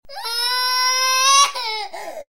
cry1.wav